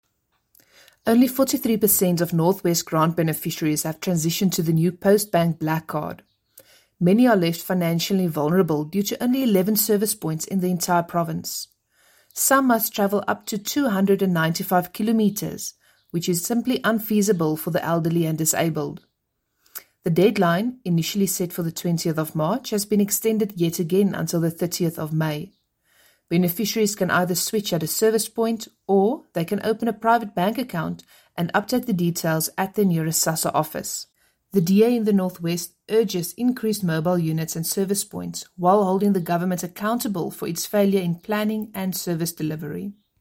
Note to Broadcasters: Please find attached soundbites in